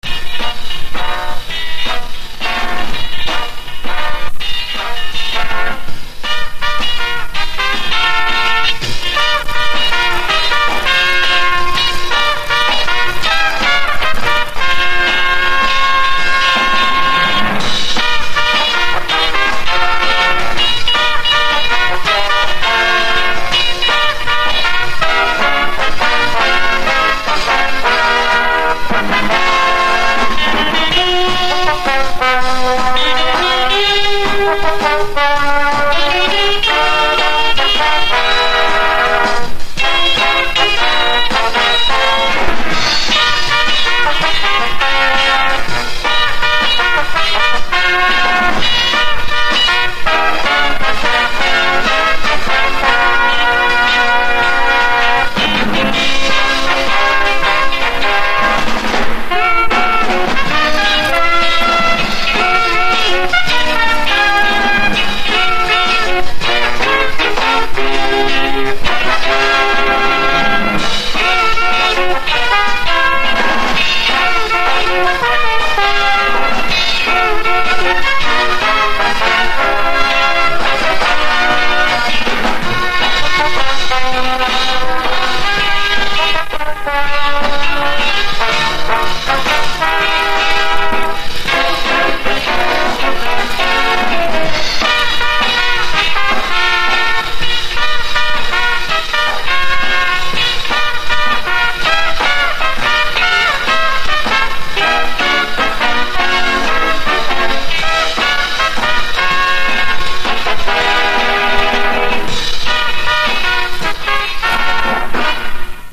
Три оркестровых инструменталки 60-х для опознания (повтор)
instrumental-v-ispolnenii-estradnogo-orkestra-(zapis-60-h-godov)-3.mp3